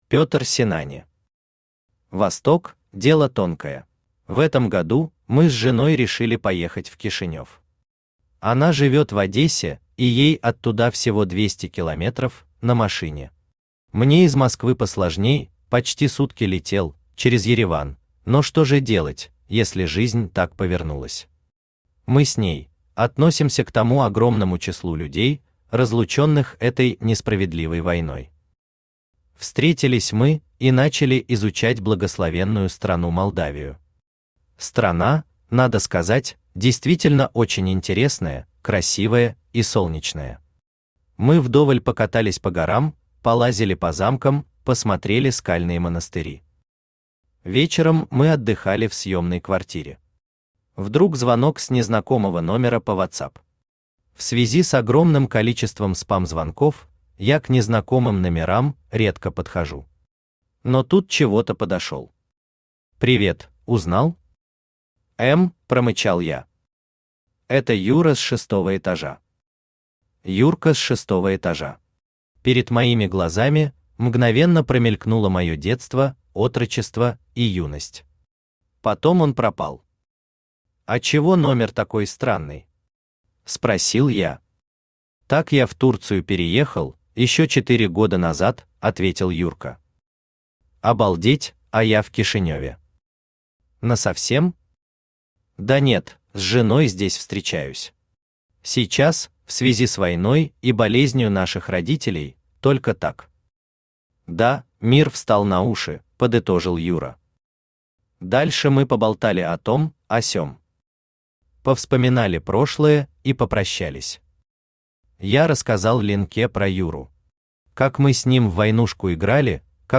Aудиокнига Восток – дело тонкое Автор Петр Синани Читает аудиокнигу Авточтец ЛитРес.